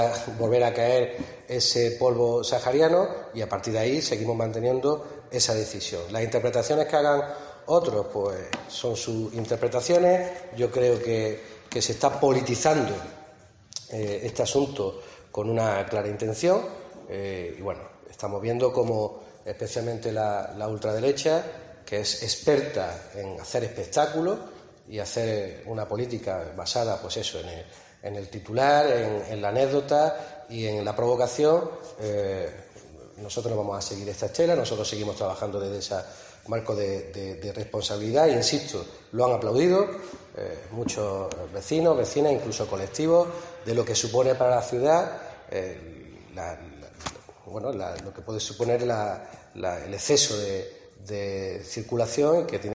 Jacobo Calvo, portavoz del equipo de gobierno